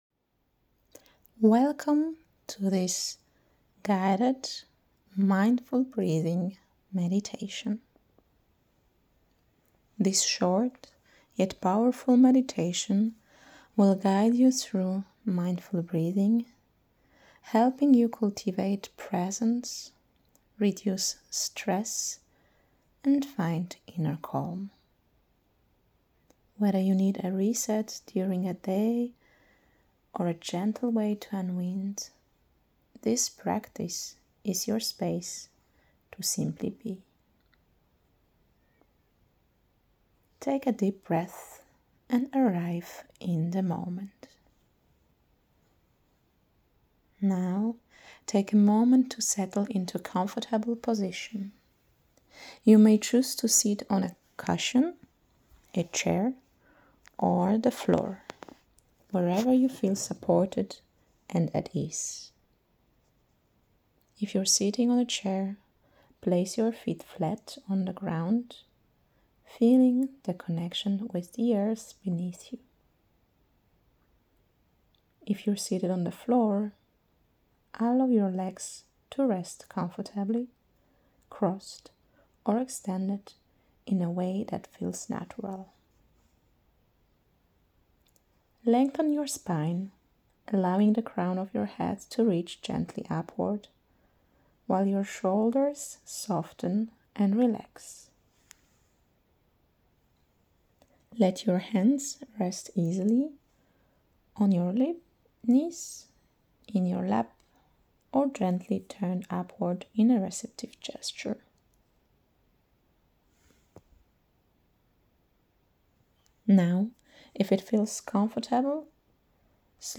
10 Mins Guided Mindful Breathing Meditation